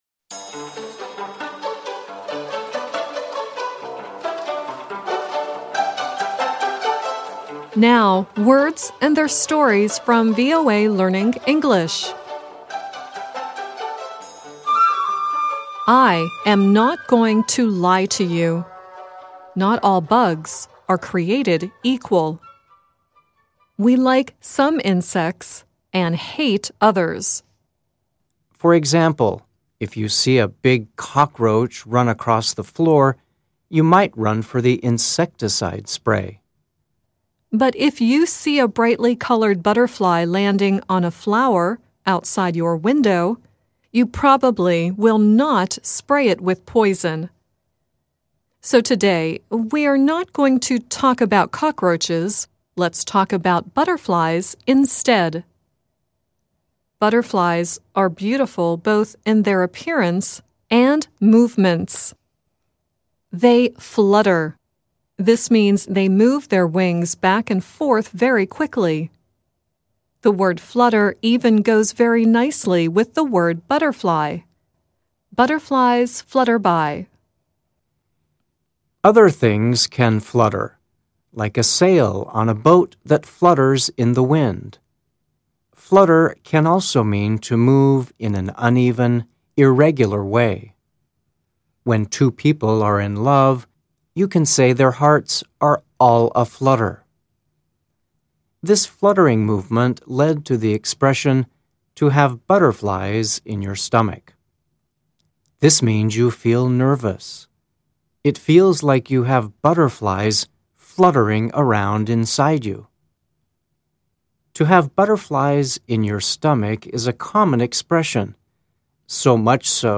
The song at the end is Diana Ross singing "Chain Reaction."